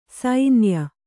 ♪ sainya